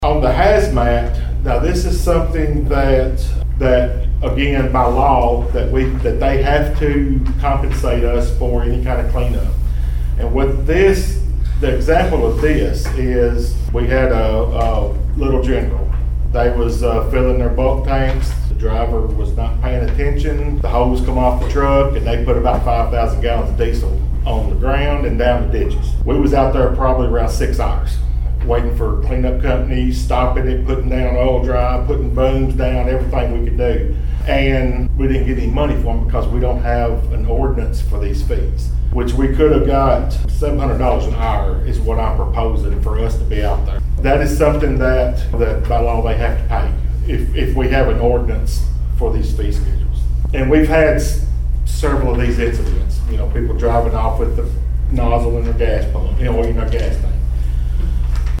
Martin Fire Chief Jamie Summers shared an example of why the proposal is needed with the Finance Committee.